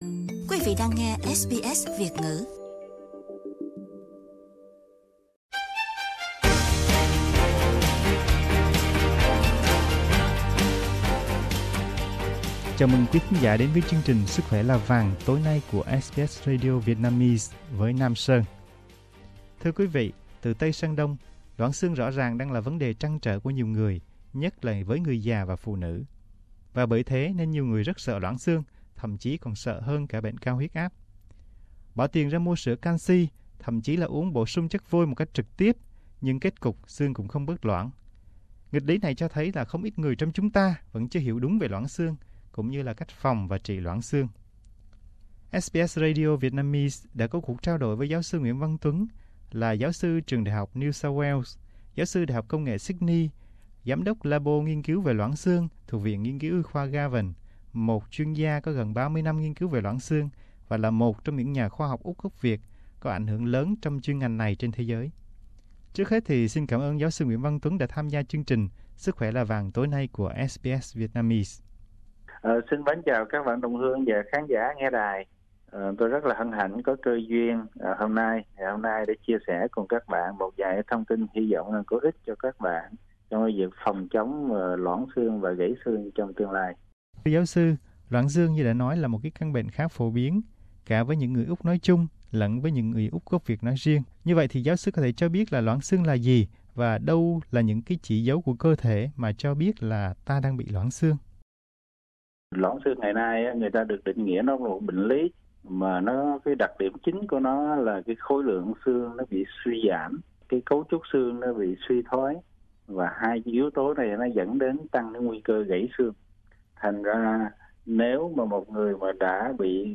SBS Radio có cuộc trao đổi